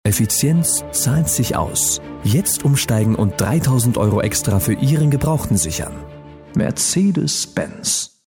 Sprachproben von Maximilian Arland
Maximilian Arland spricht
Mit seiner markanten Stimme hat er seinen Schwerpunkt auf das Sprechen von Werbespots und die Vertonung von TV- und Radio-Dokumentationen sowie Imagefilmen gelegt.